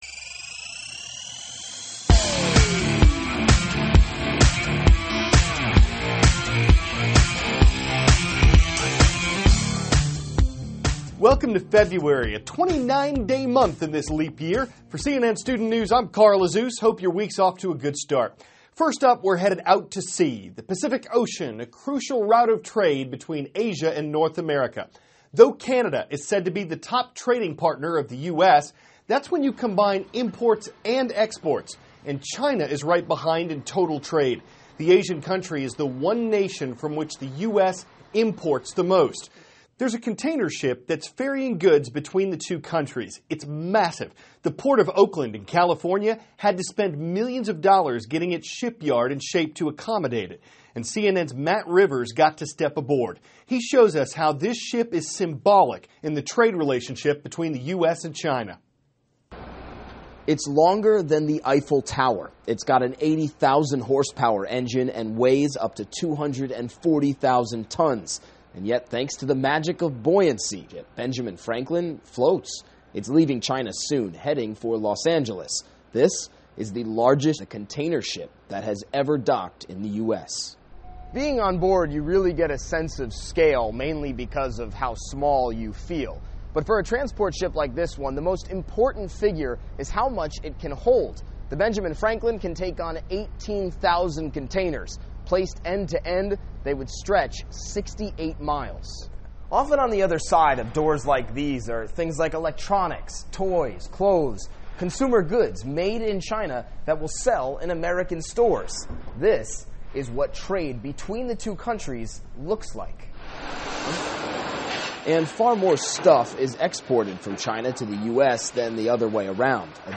(CNN Student News) -- February 1, 2016 Massive Ship Symbolizes U.S.-China Trade; The Significance of the Iowa Caucuses. Aired 4-4:10a ET THIS IS A RUSH TRANSCRIPT.